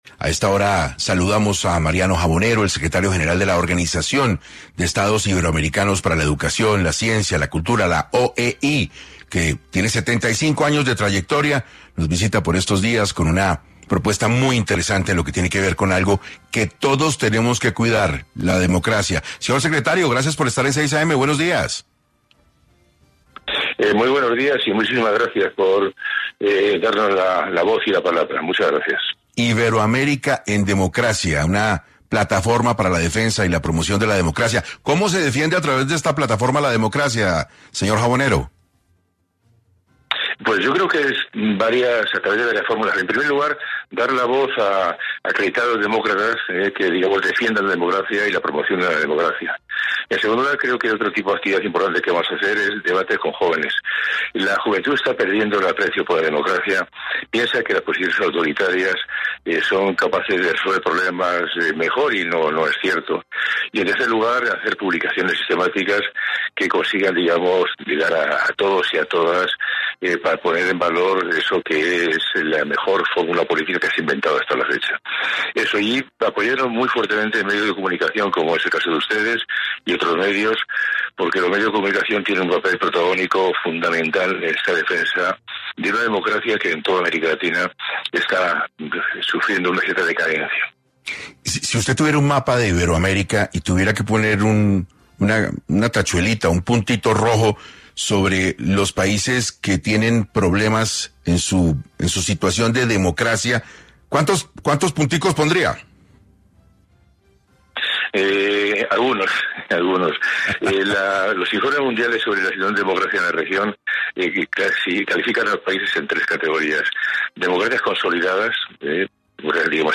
En 6AM de Caracol Radio, el secretario de la OEI, Mariano Jabonero, explicó como esta plataforma promueve las buenas prácticas democráticas y busca resolver algunos de los problemas que se presentan en los actuales gobiernos.